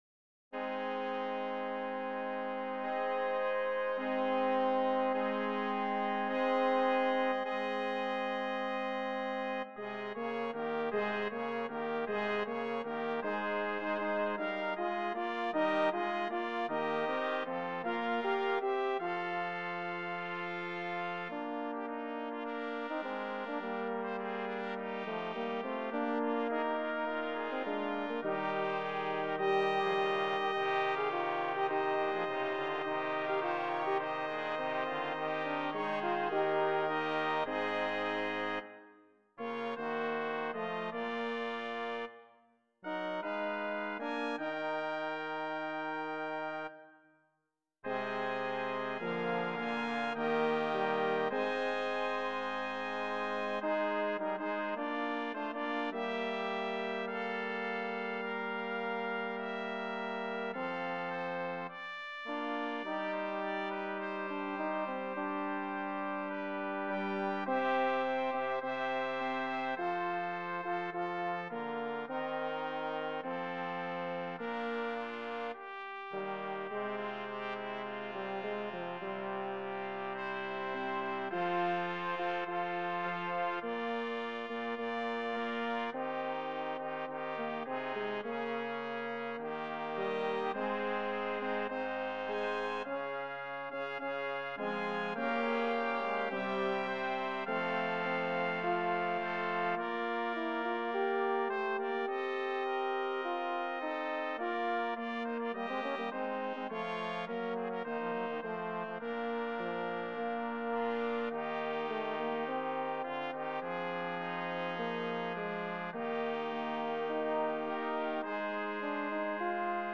DOUBLE BRASS CHOIR
PENTECOST MOTET